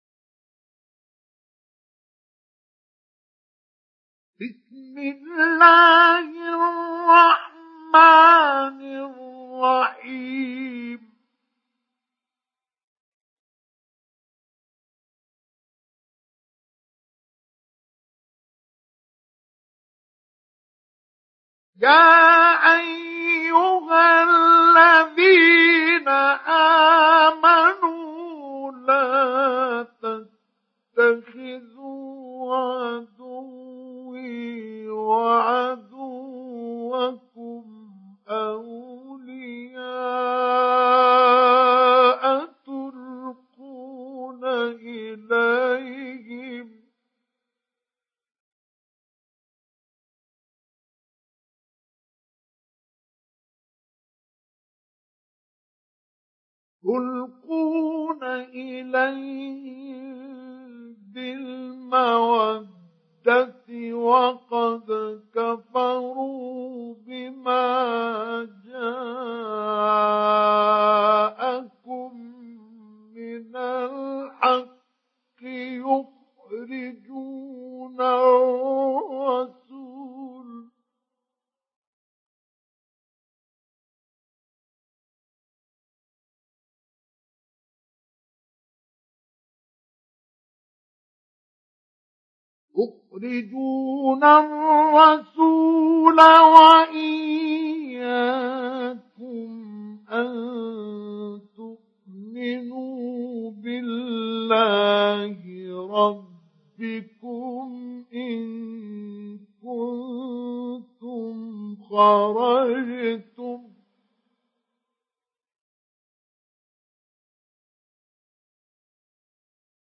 سُورَةُ المُمۡتَحنَةِ بصوت الشيخ مصطفى اسماعيل